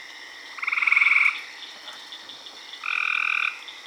In this short excerpt, you hear one Hyla versicolor call followed by a single Hyla chrysoscelis call.  You can hear how much drier and more buzzy the H. chrysoscelis call is than the bird-like nature of the H. versicolor call.
Two Gray Treefrog calls - Hyla versicolor then Hyla chrysocelis mp3
This difference is due to the speed of the trill.
This is a direct sample from recording in the field where both species were calling together at the same pond at the same time.
That is a pulse frequency of 31.7 pulses per second.
The second call (Hyla versicolor) consists of 25 pulses in 0.42 seconds for a pulse frequency of 59.5 pulses per second.